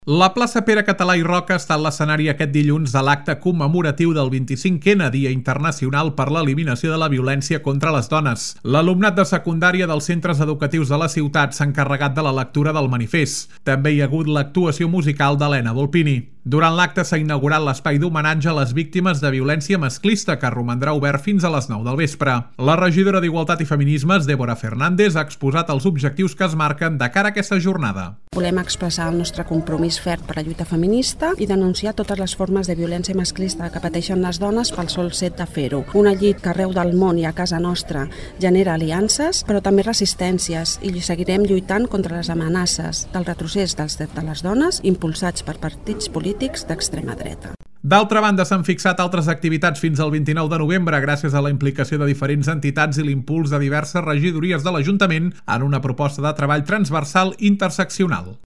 La plaça Pere Català i Roca ha estat l’escenari aquest dilluns de l’acte commemoratiu del 25N, Dia Internacional per a l’Eliminació de la Violència contra les Dones.
La regidora d’Igualtat i Feminismes, Dèbora Fernández, ha exposat els objectius que es marquen de cara a aquesta jornada.